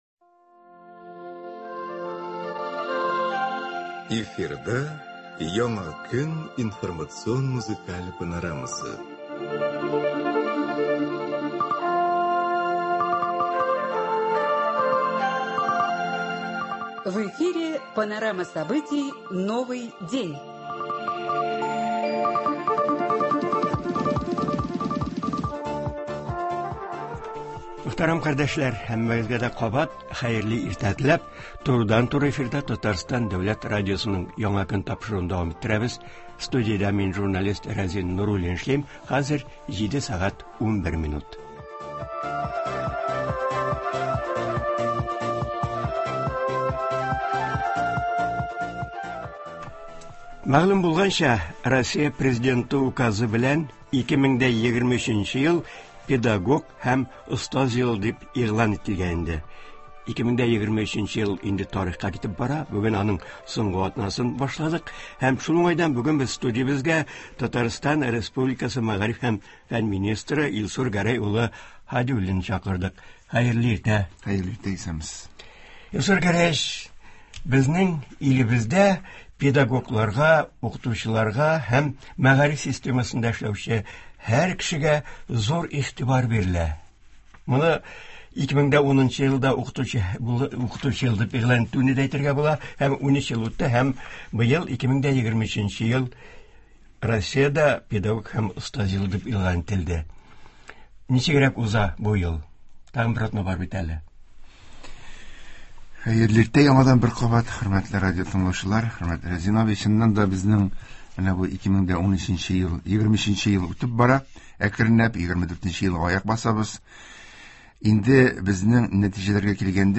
Уку елының беренче яртысын Татарстан мәгариф системасы ничек төгәлли? Болар хакында турыдан-туры эфирда Татарстан республикасы мәгариф һәм фән министры Илсур Гәрәй улы Һадиуллин сөйләячәк, тыңлаучылар сорауларына җавап бирәчәк.